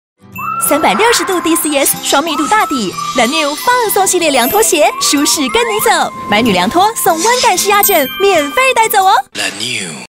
國語配音 女性配音員
她在廣告中憑藉真摯而又帶有親和力的聲音，成功塑造出鮮明的品牌形象，是業界廣告配音的熱門選擇。